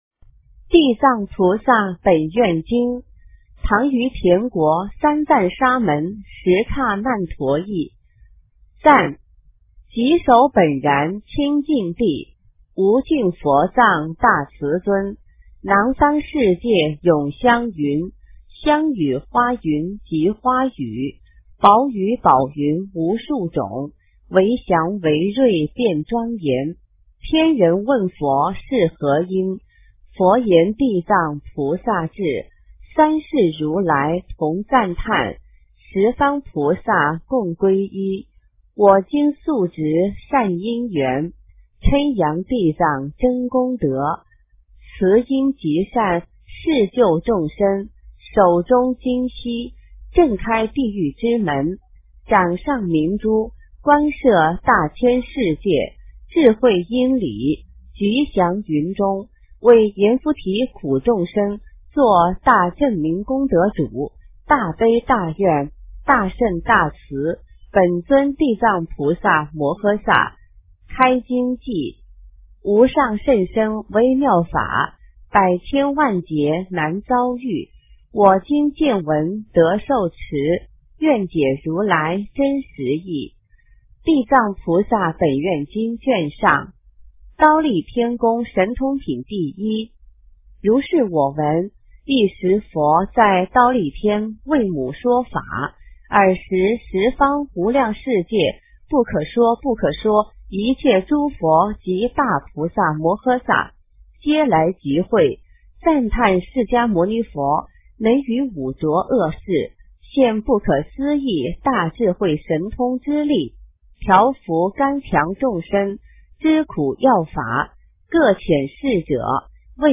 地藏菩萨本愿经 - 诵经 - 云佛论坛